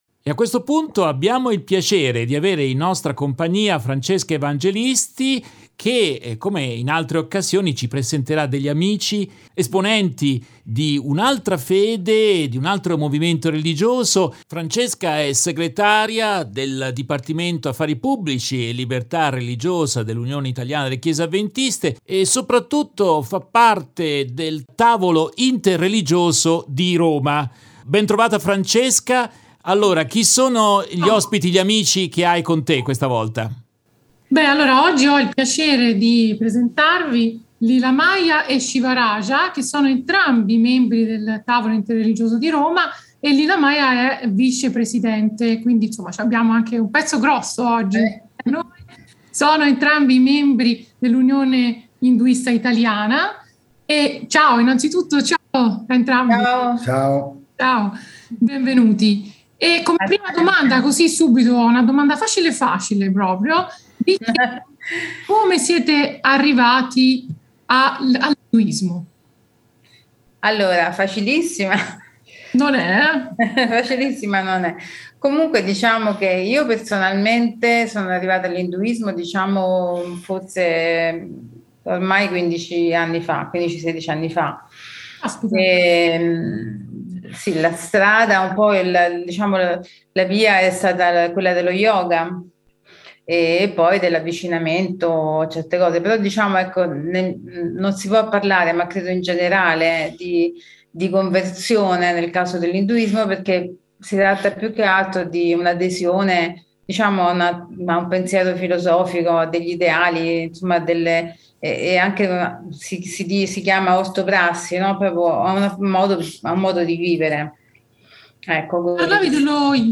Nel corso della diretta RVS del 24 giugno